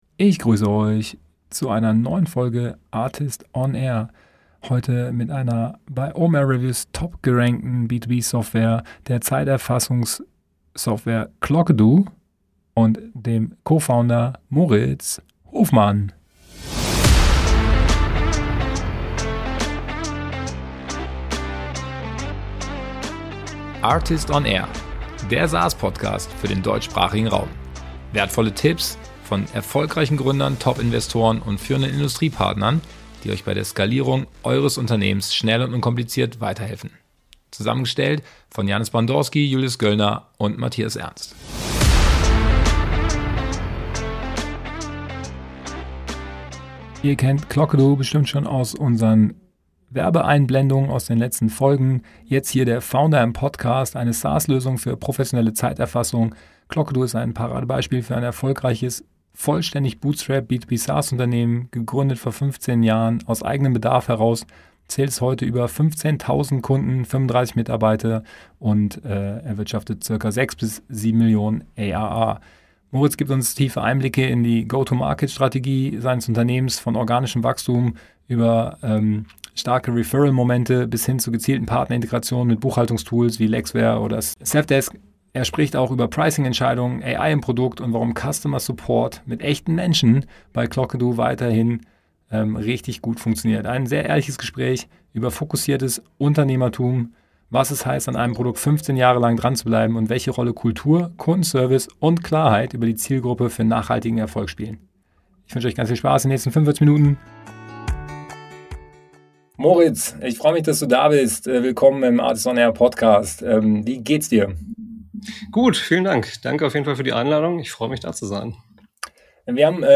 Ein ehrliches Gespräch über fokussiertes Unternehmertum, was es heißt, an einem Produkt 15 Jahre lang dranzubleiben – und welche Rolle Kultur, Kundenservice und Klarheit über die Zielgruppe für nachhaltigen Erfolg spielen.